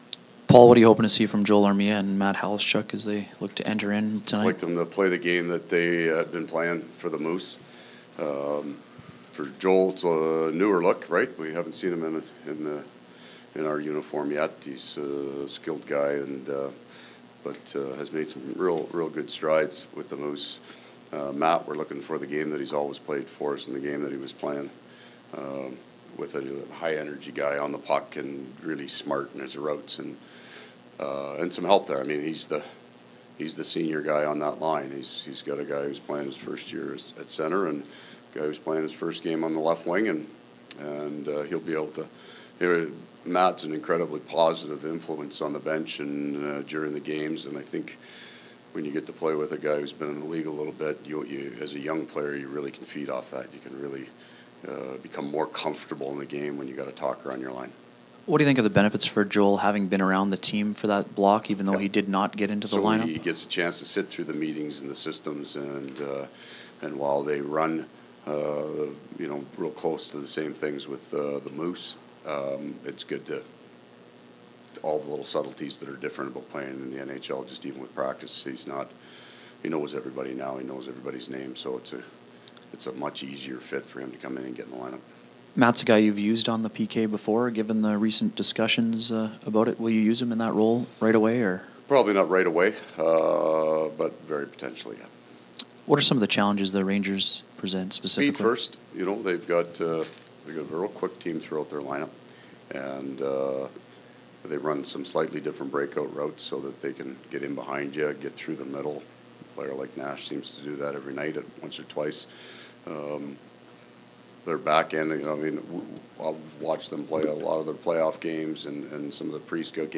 Coach pre-game scrum
Winnipeg Jets coach Paul Maurice pre-game scrum (Rangers)
Coach Maurice’s game day comments.